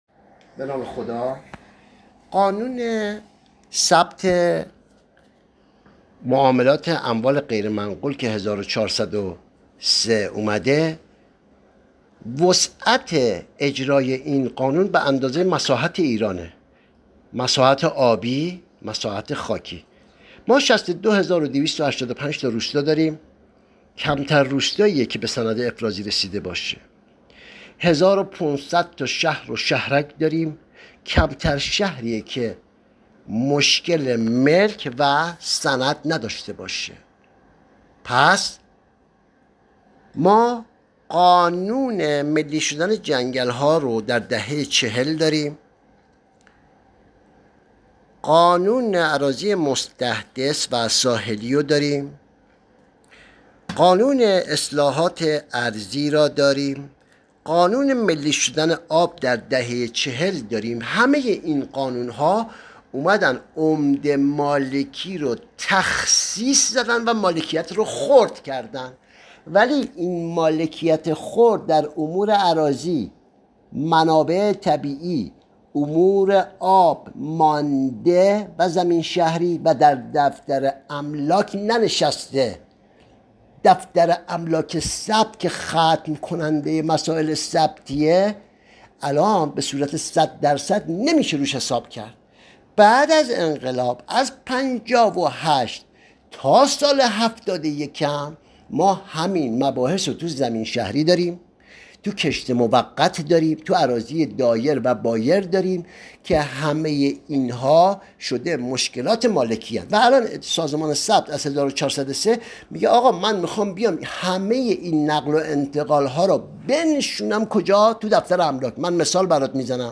پادکست صوتی گفت وگو